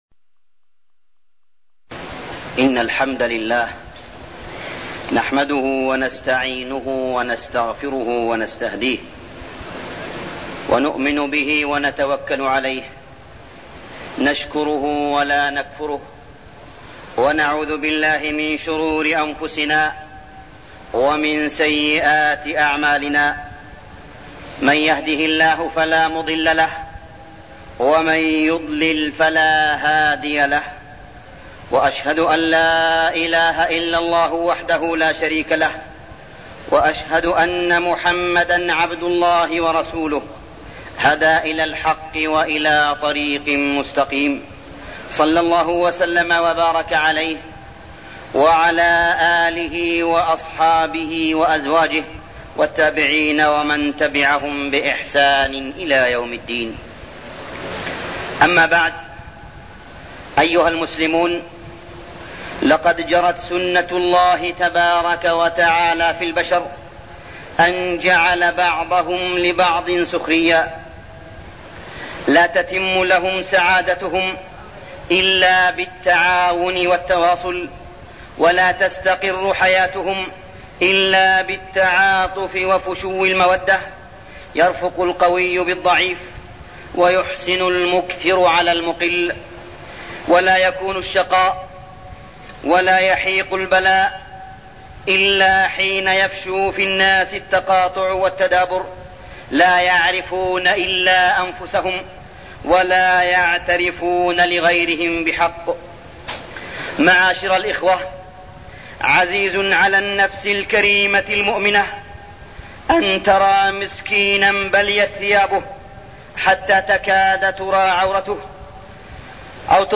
تاريخ النشر ٢٣ شوال ١٤١٠ هـ المكان: المسجد الحرام الشيخ: معالي الشيخ أ.د. صالح بن عبدالله بن حميد معالي الشيخ أ.د. صالح بن عبدالله بن حميد وجوب شكر النعم